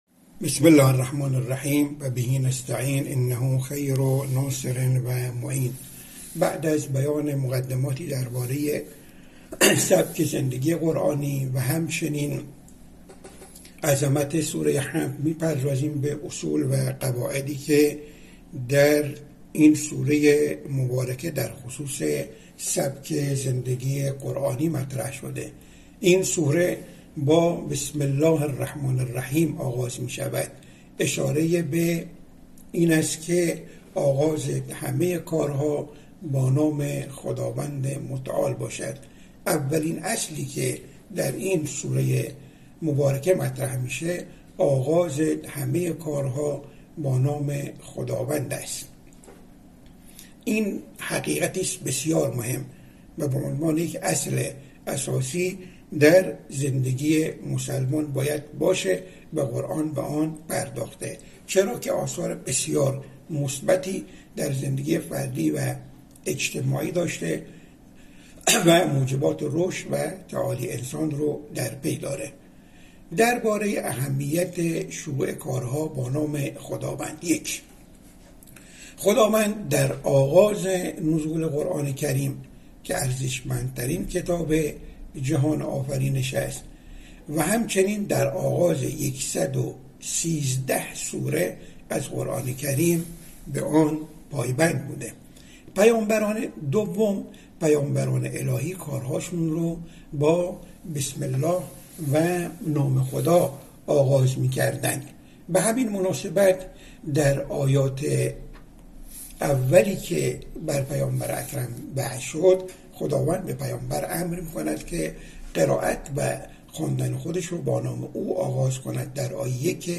یک پژوهشگر تفسیر در اهمیت شروع کارها با نام خداوند به بیان پنج نکته براساس آیات قرآن و روایات پرداخت.